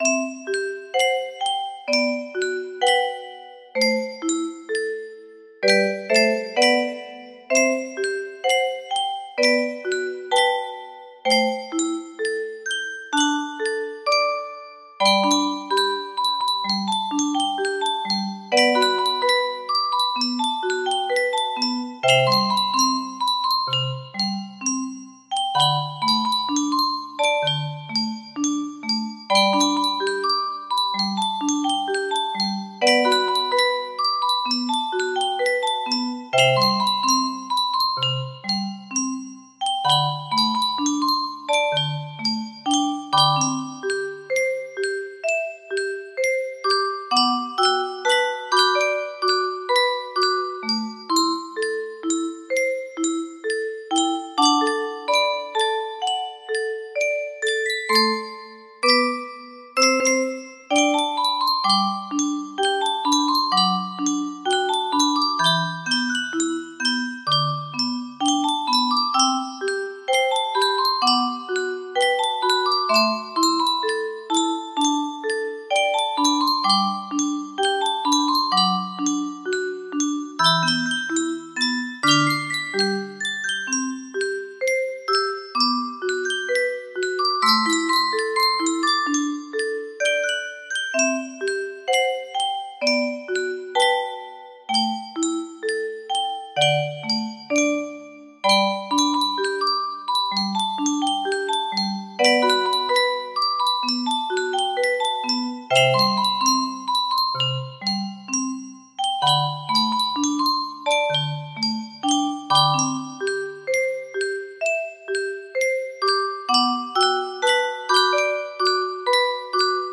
Music boxดูแล music box melody
Full range 60